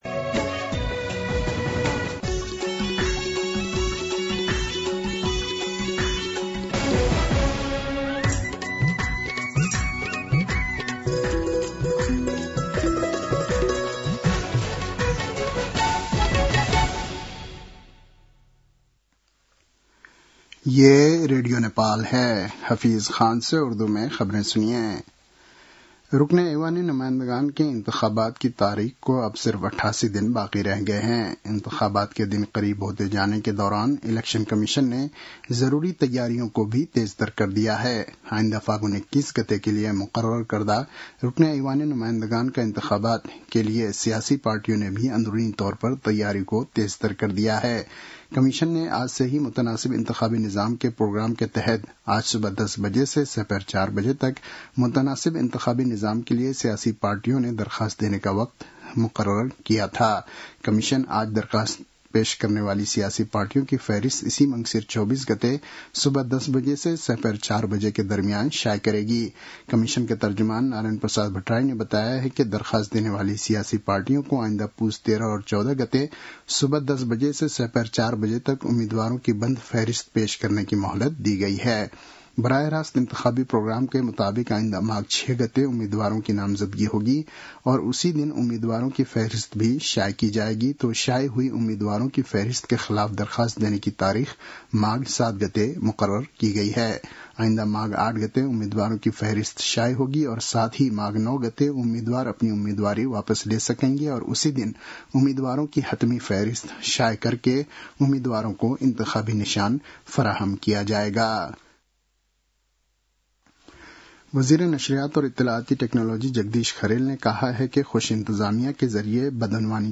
उर्दु भाषामा समाचार : २१ मंसिर , २०८२